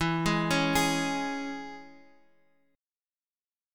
E6 chord